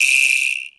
add drum sound samples from scratch 2.0
Vibraslap(1)_22k.wav